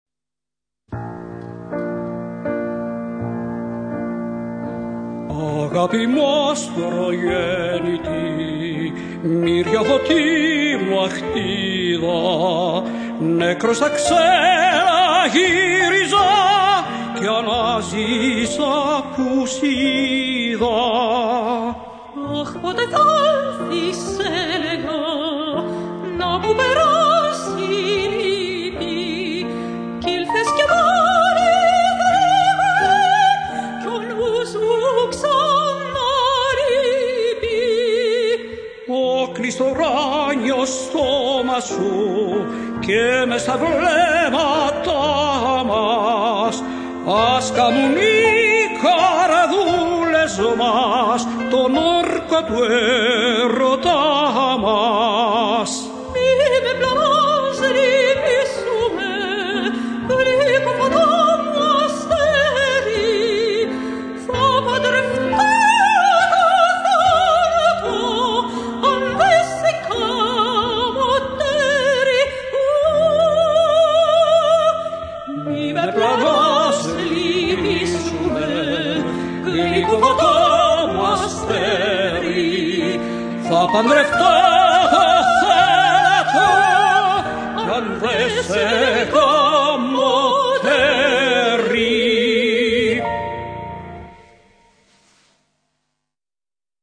Nτουέτο «Ο κόντε Σπουργίτης»
σοπράνο
τενόρο
πιάνο